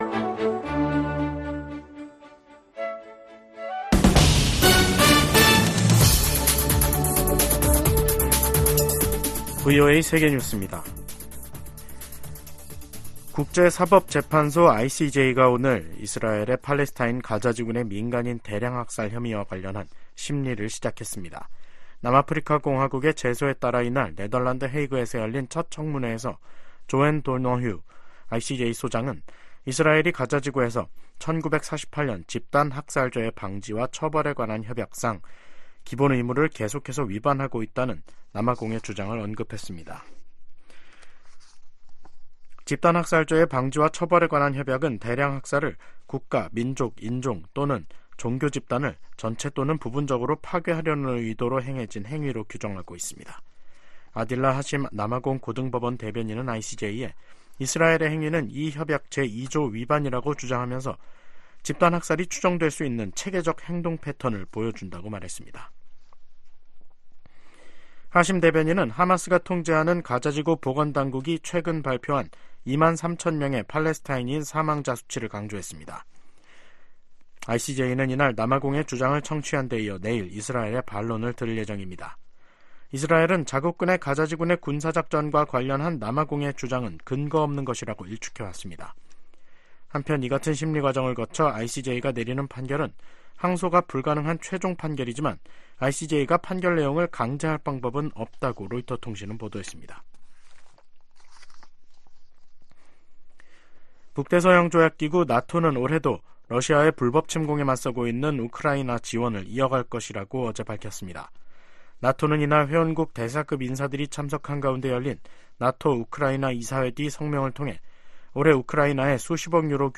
VOA 한국어 간판 뉴스 프로그램 '뉴스 투데이', 2024년 1월 11일 2부 방송입니다. 백악관은 팔레스타인 무장정파 하마스가 북한 무기를 사용한 사실을 인지하고 있다고 밝혔습니다. 미국, 한국, 일본 등이 유엔 안보리 회의에서 러시아가 북한에서 조달한 미사일로 우크라이나를 공격하고 있는 것을 강력하게 비판했습니다. 미국은 중국과의 올해 첫 국방 정책 회담에서 북한의 최근 도발에 우려를 표명하고 철통 같은 인도태평양 방위 공약을 재확인했습니다.